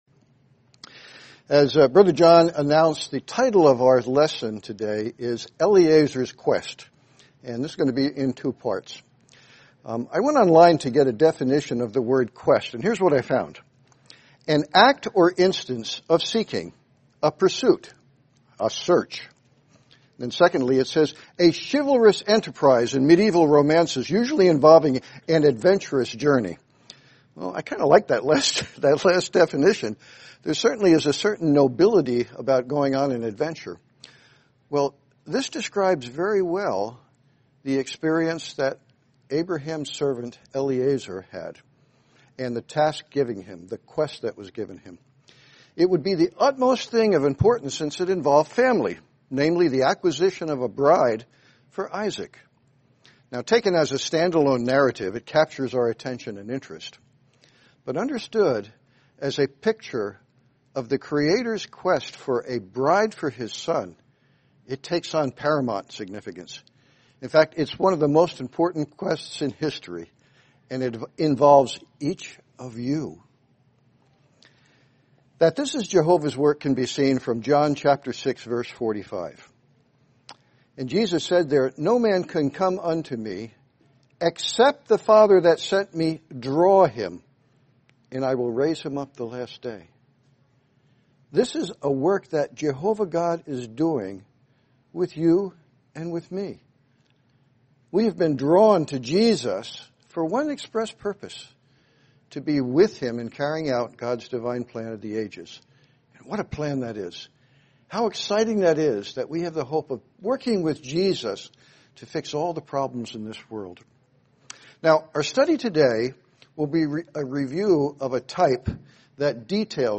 Series: 2025 Wilmington Convention
Service Type: Sermons